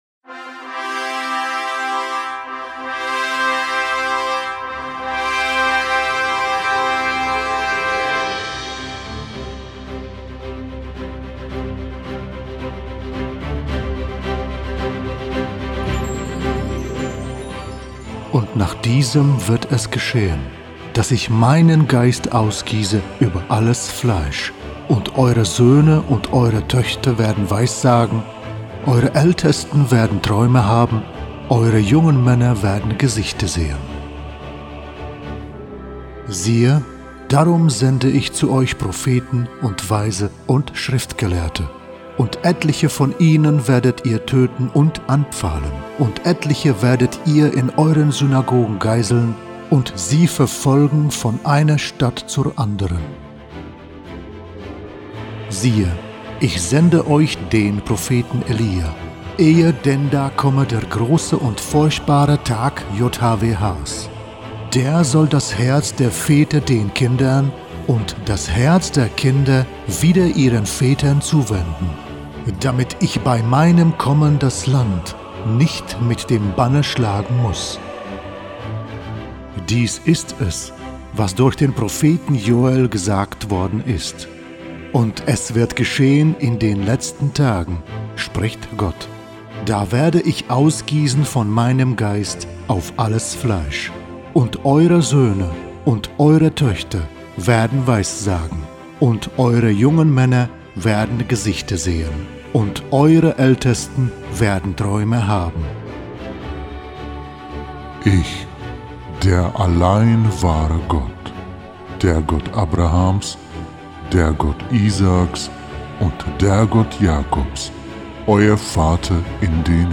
060. Deine Nahrung entscheidet über dein Wohlergehen als Hörbuch in MP3